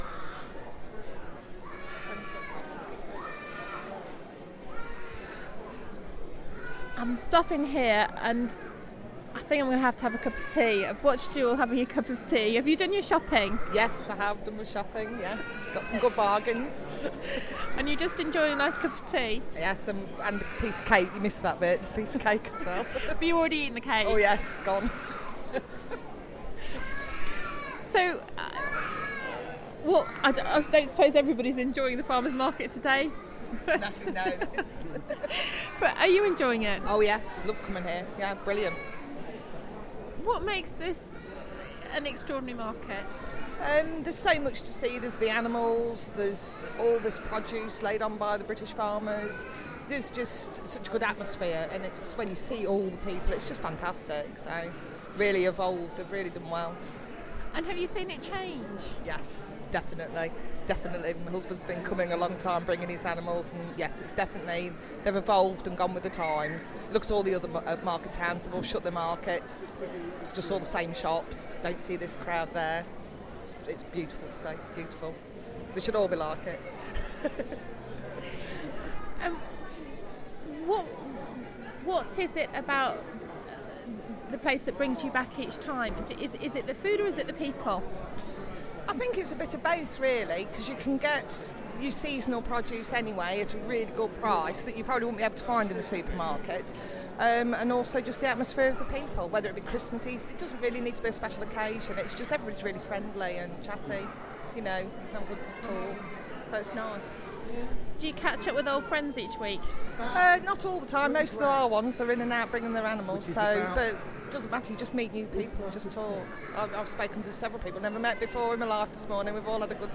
Stopping for a cup of tea, and chatting with a farmers wife .. she was happy to explain why she loves the Melton Mowbray farmers market so much.